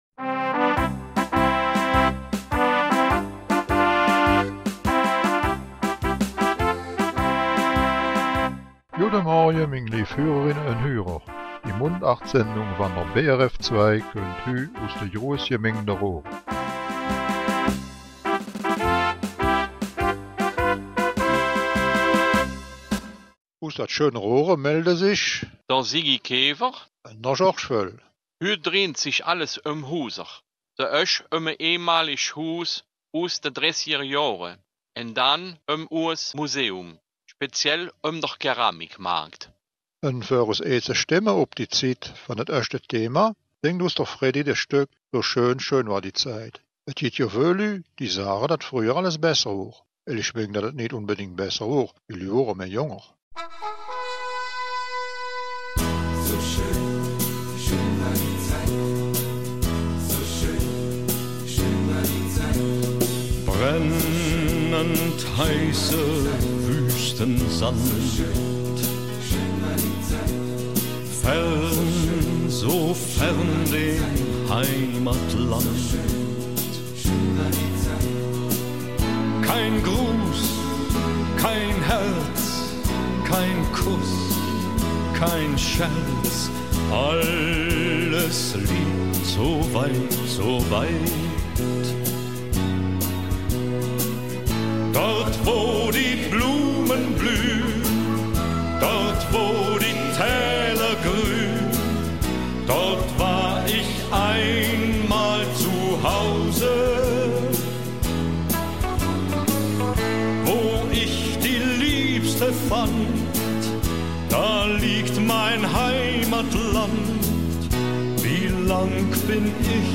Raerener Mundartsendung - 28. August